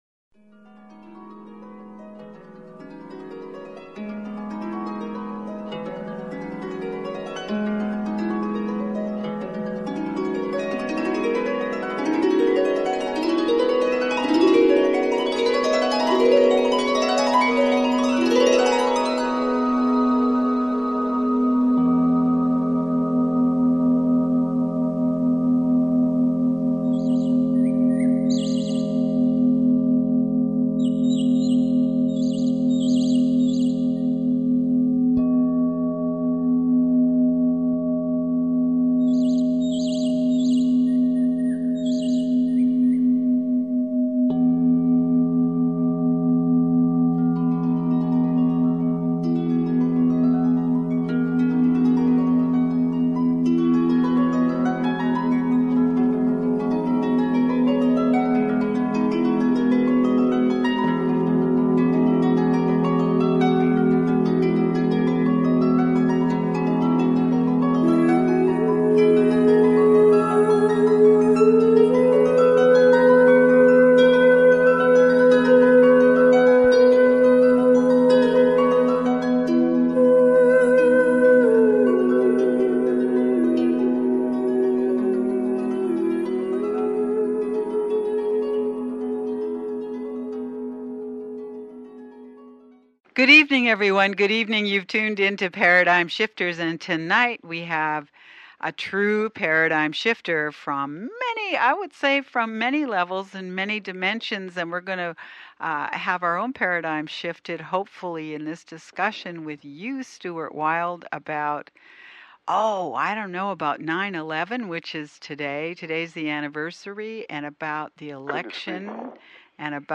Guest, Stuart Wilde